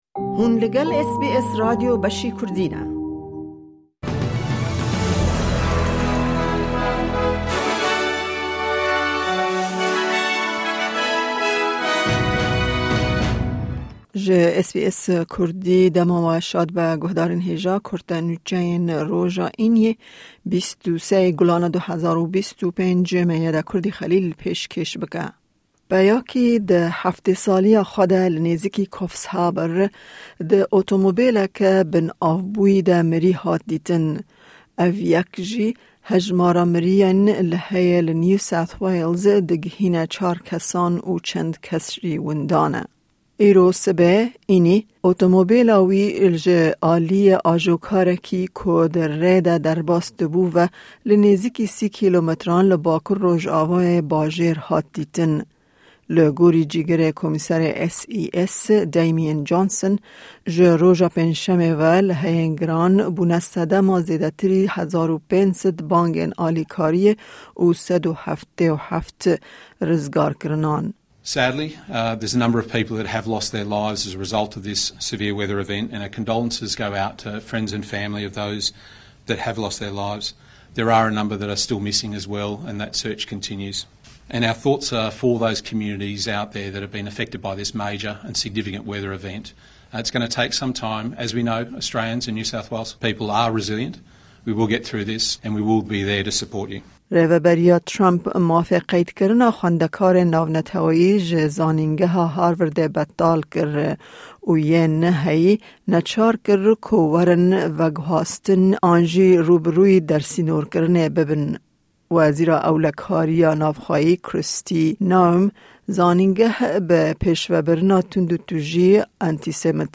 Kurte Nûçeyên roja Înî 23î Gulana 2025